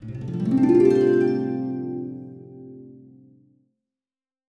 Magical Harp (5).wav